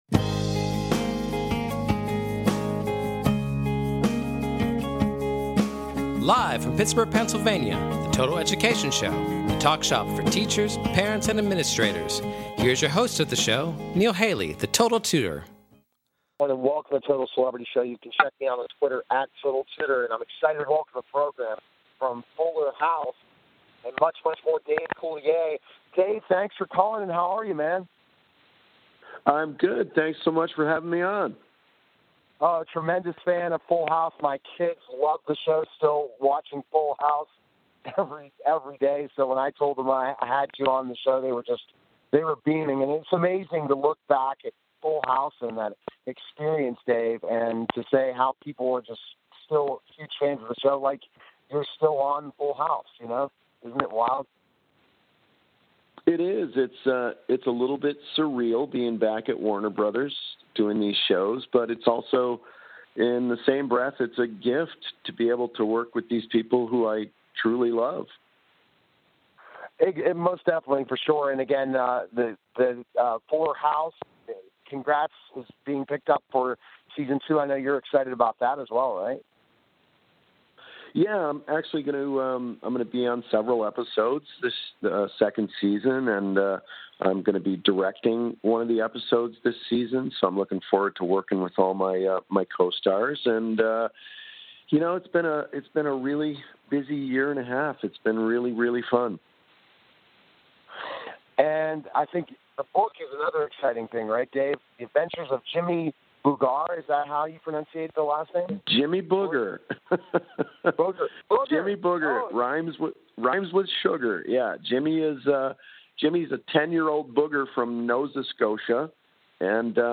Catch weekly discussions focusing on current education news at a local and national scale.